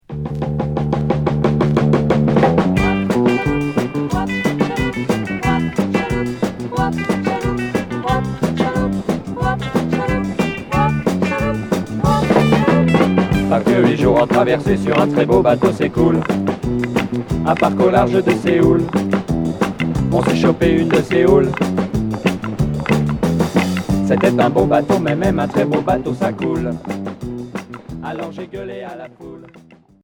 Pop rock ska